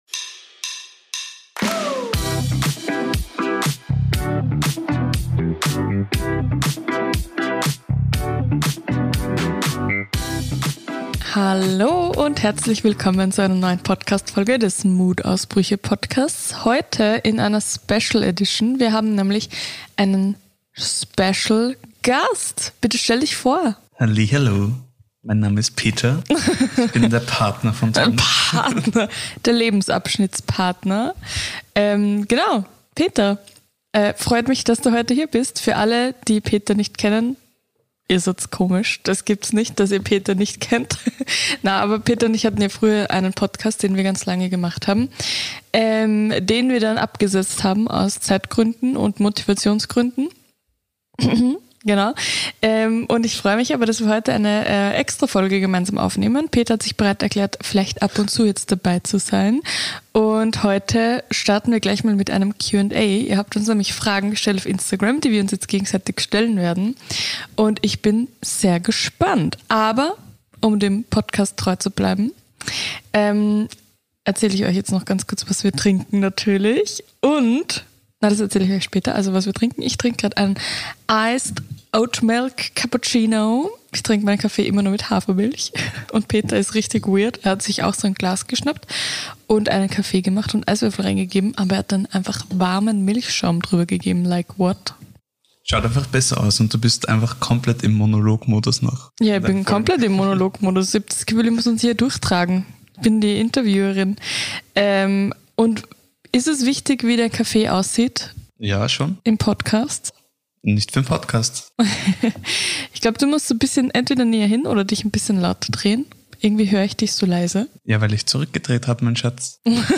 Die heutige Folge ist eine ganz Besondere – ich habe nämlich einen Special Guest eingeladen, der dem einen oder anderen vermutlich auch bekannt vorkommt!